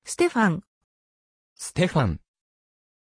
Aussprache von Stefan
pronunciation-stefan-ja.mp3